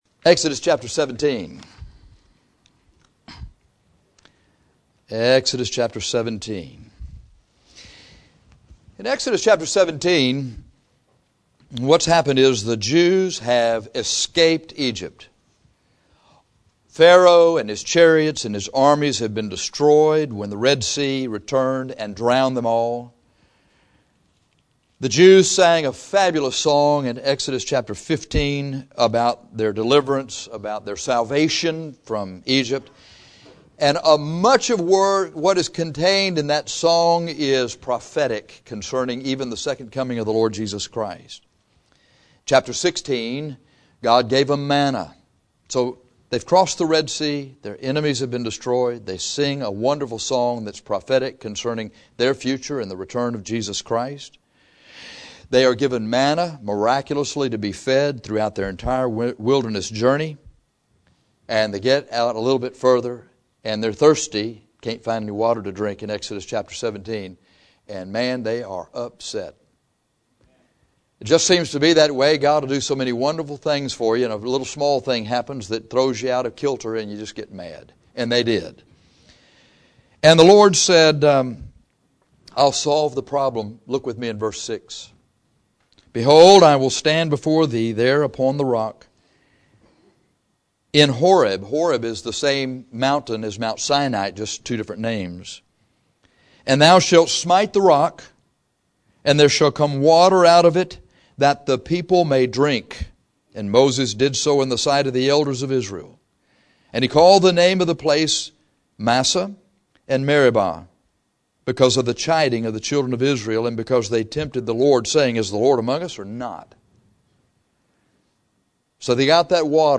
The picture in this text is the picture of our fight against sin and our need to win. This sermon is how to defeat Amalek.